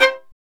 Index of /90_sSampleCDs/Roland - String Master Series/STR_Violin 2&3vb/STR_Vln2 % + dyn